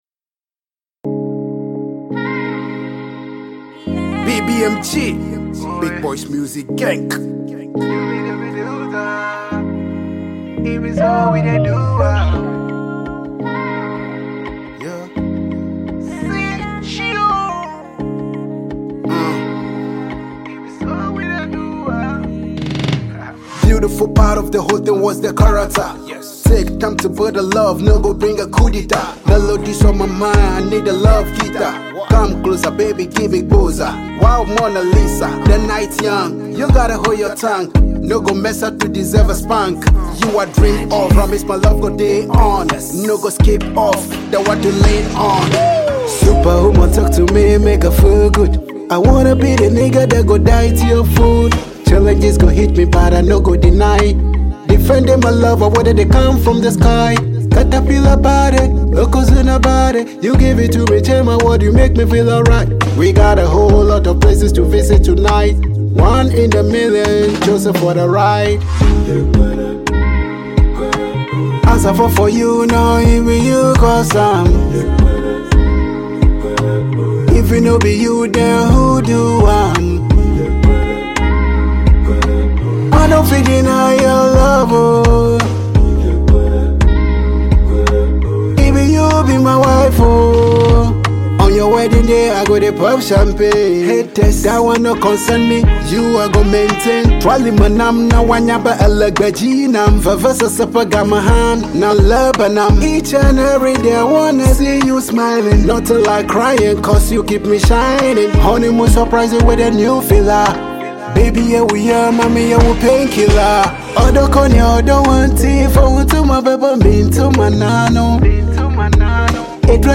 a soulful and infectious tune